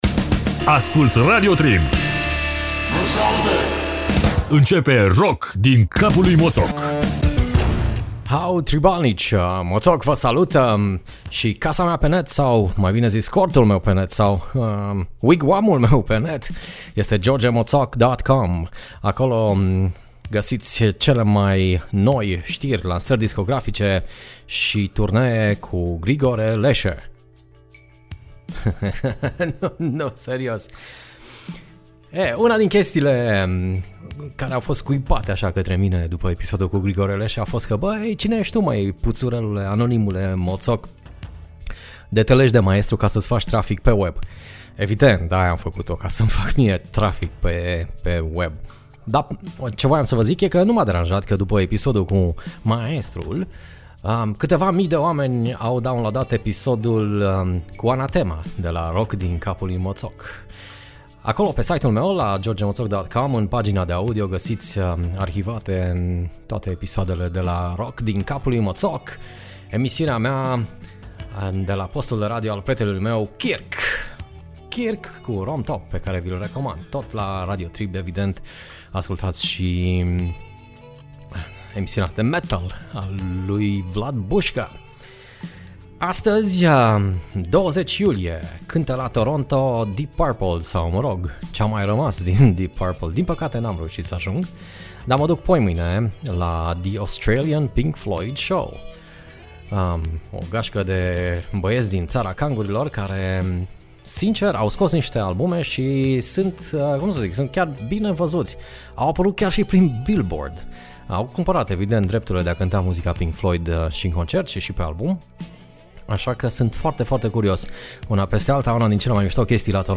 Emisiune difuzata initial live la Radio Trib.